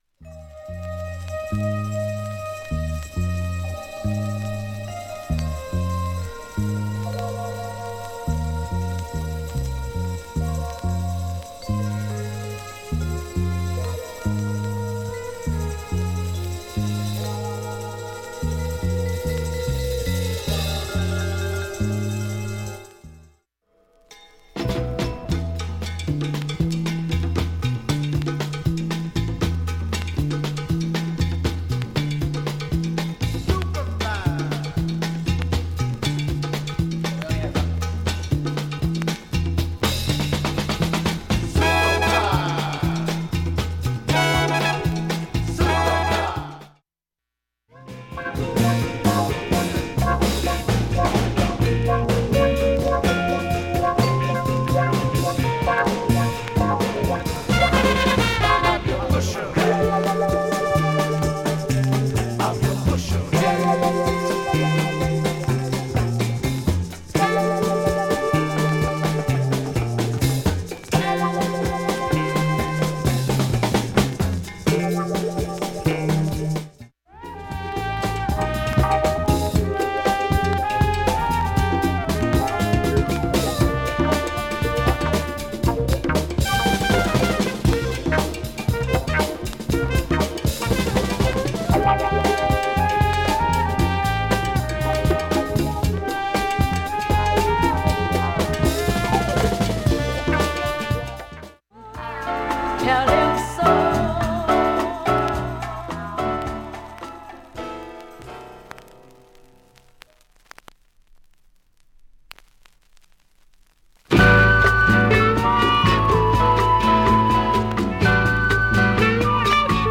音質良好全曲試聴済み。
５回までのかすかなプツが１箇所
３回までのかすかなプツ７箇所
単発のかすかなプツが６箇所